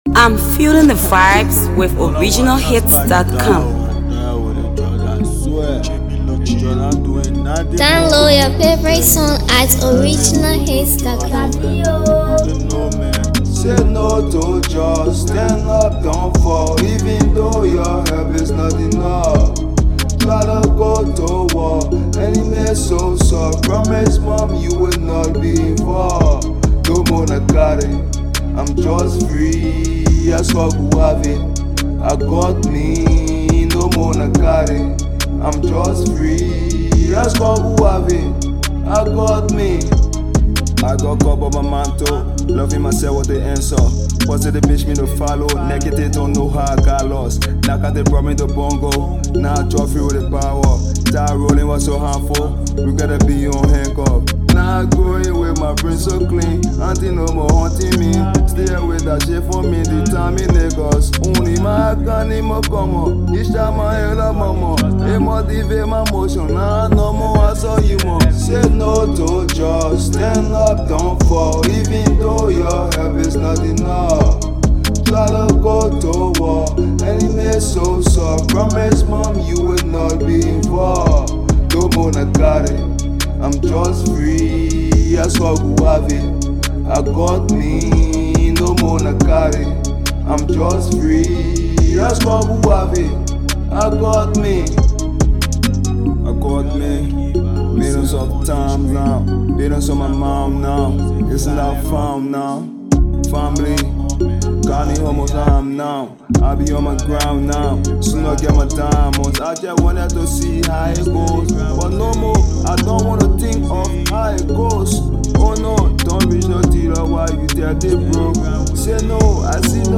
powerful awareness song